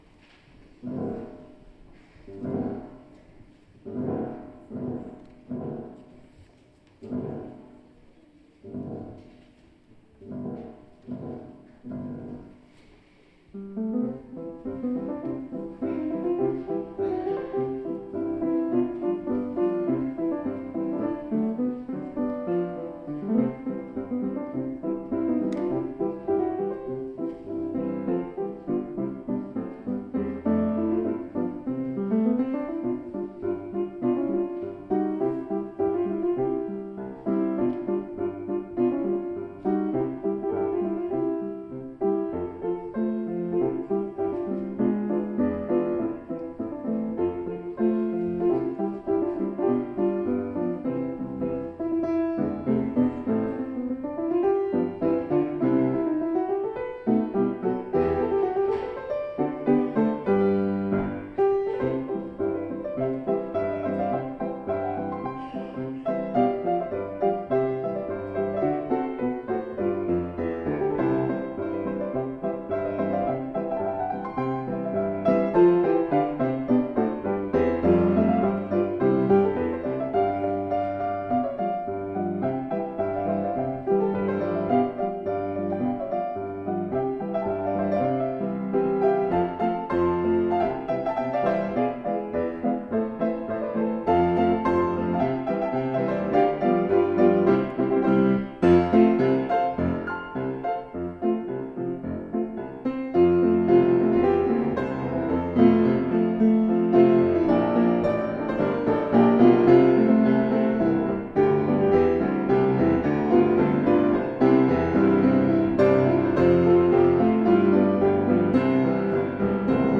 little more sanguine in character than yesterday's selections.